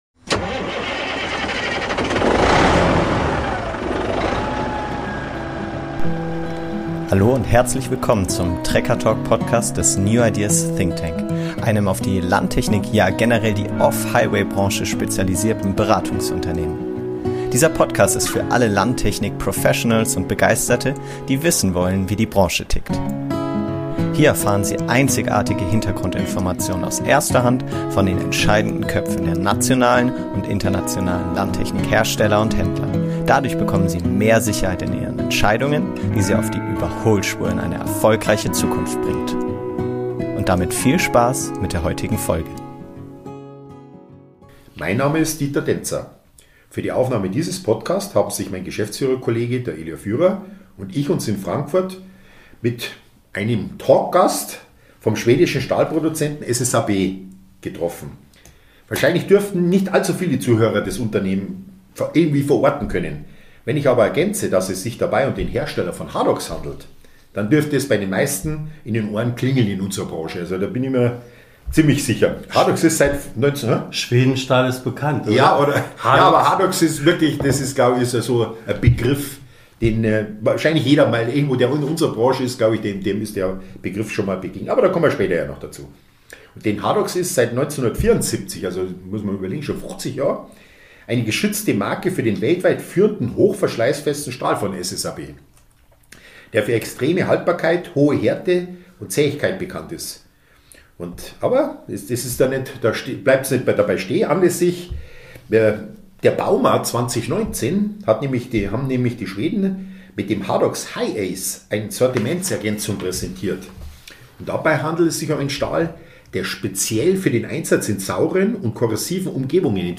Im Talk führte er uns mit großer Expertise und bemerkenswerter Klarheit durch die Entwicklungsgeschichte und Zukunftsaussichten der Spezialstähle.